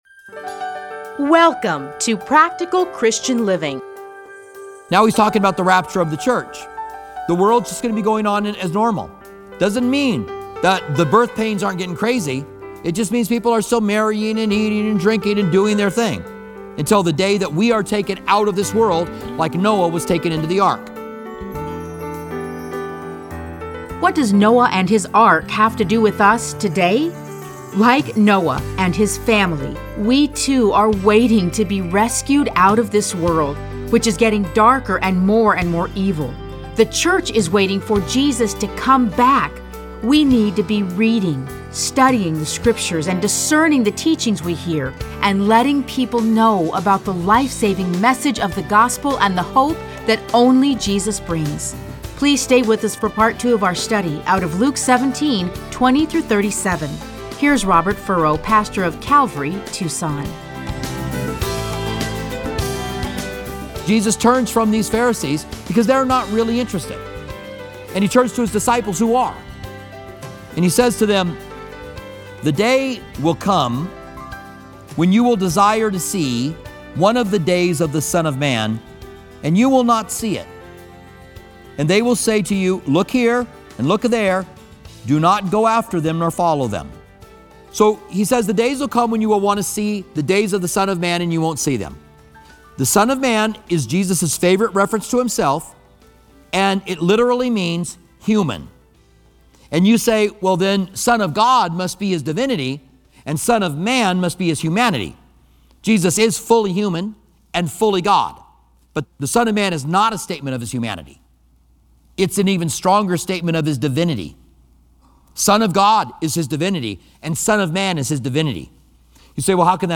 Listen to a teaching from Luke 17:20-37.